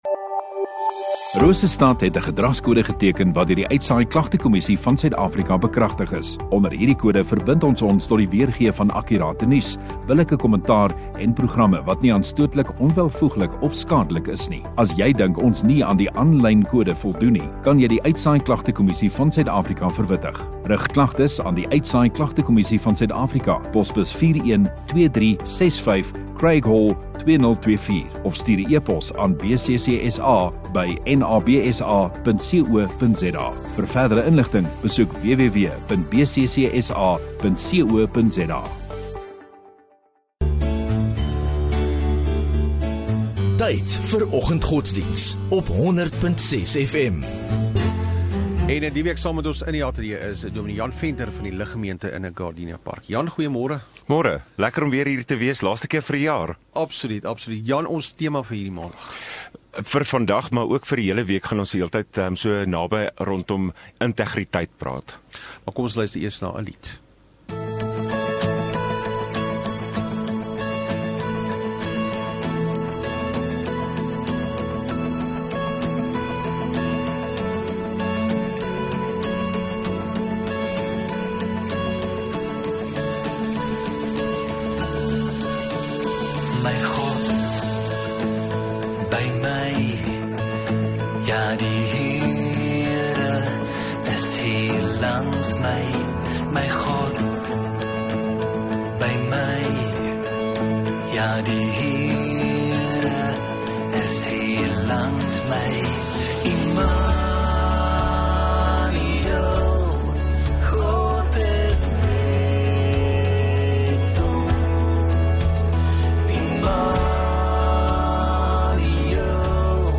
4 Nov Maandag Oggenddiens